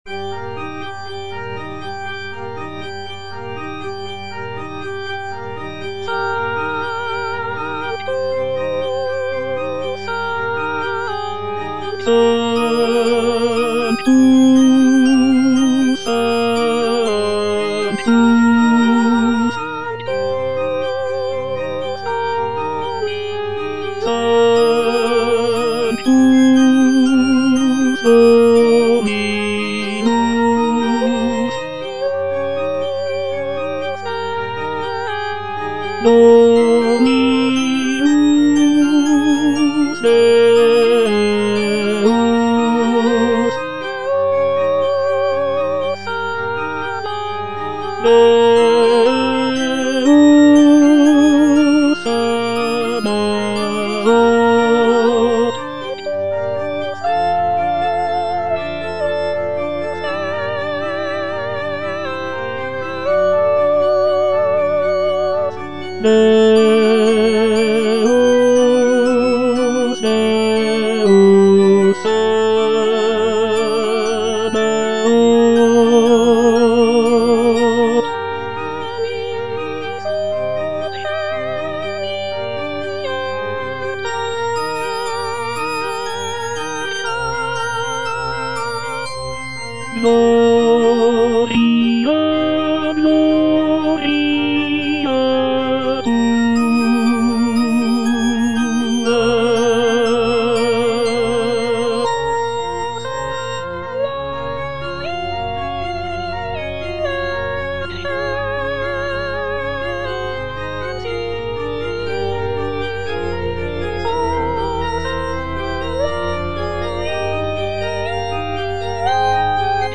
G. FAURÉ - REQUIEM OP.48 (VERSION WITH A SMALLER ORCHESTRA) Sanctus - Tenor (Emphasised voice and other voices) Ads stop: